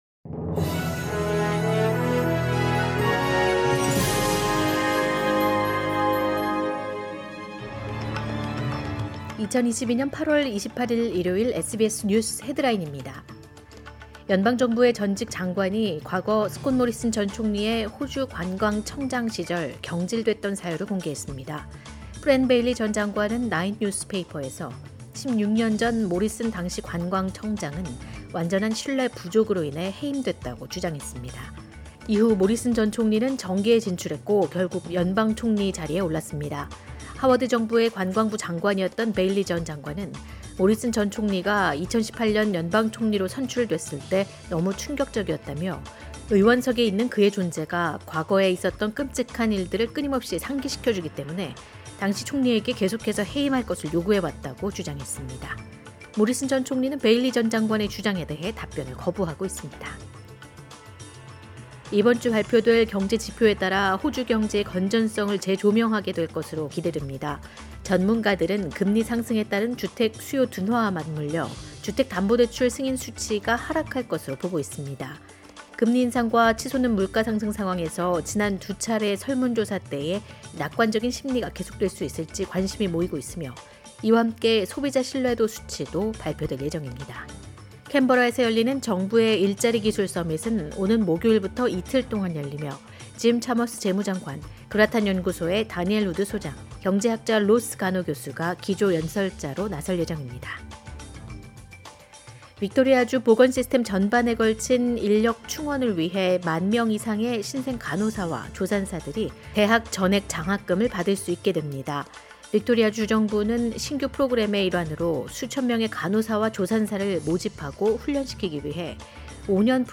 2022년 8월 28일 일요일 SBS 한국어 간추린 주요 뉴스입니다.